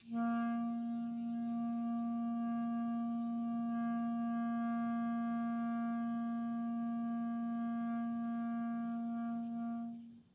Clarinet
DCClar_susLong_A#2_v1_rr1_sum.wav